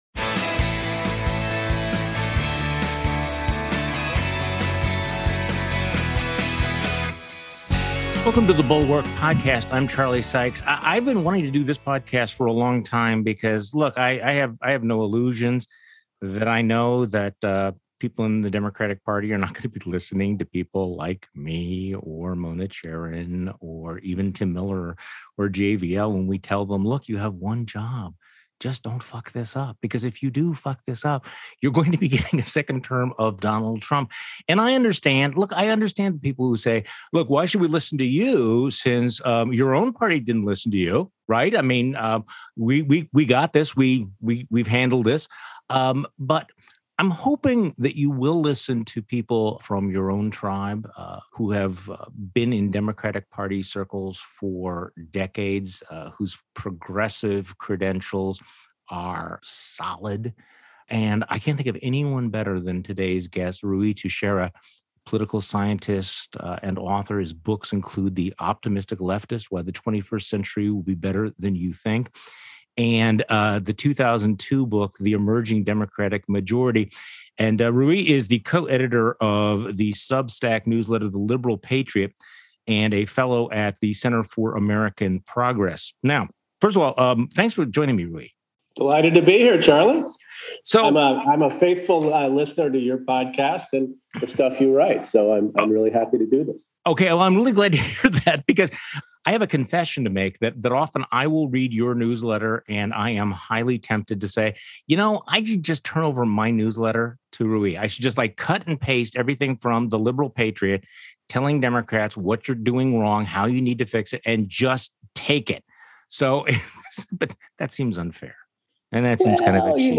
Special Guest: Ruy Teixeira.